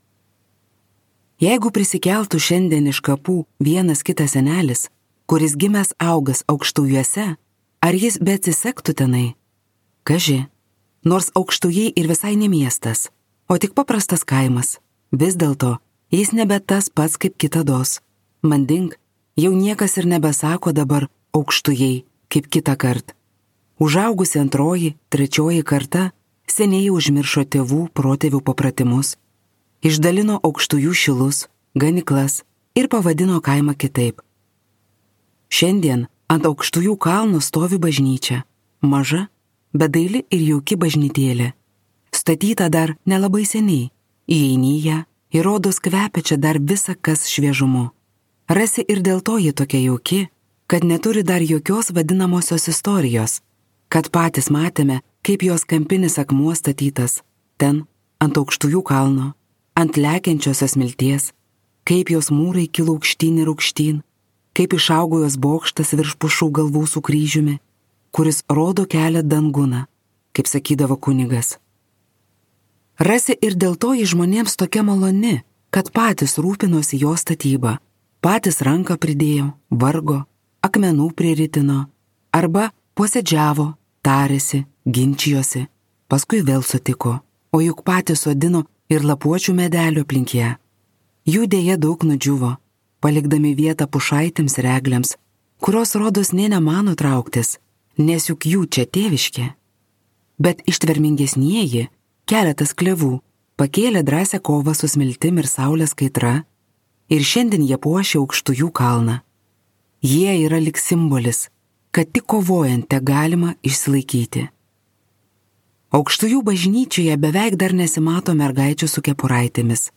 Aukštujų Šimonių likimas | Audioknygos | baltos lankos